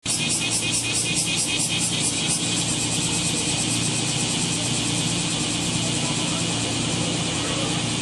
７月１８日（木） 蝉の声
先週あたりから、蝉の鳴き声が聞こえ出しました。雨が降っていない朝に聞こえます。 声はするのですが姿が見えません。